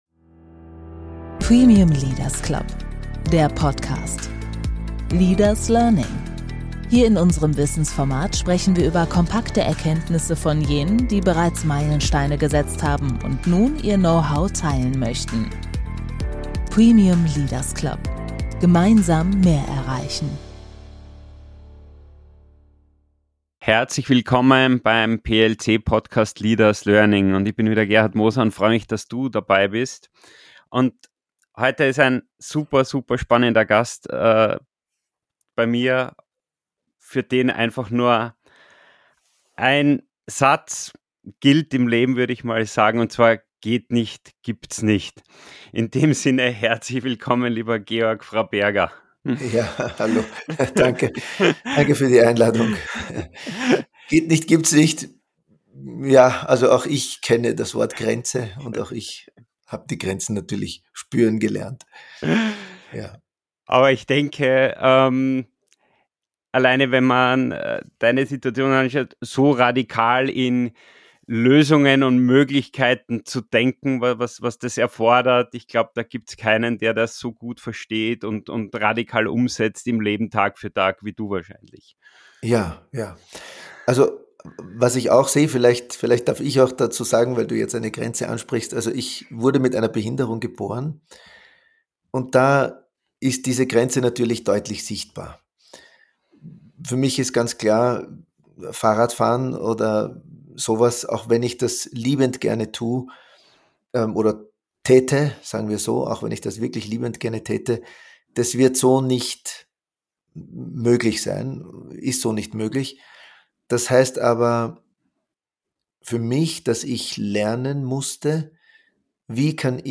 Ein berührendes und gleichzeitig messerscharfes Gespräch über Identität, Sinn und das Menschsein in modernen Zeiten.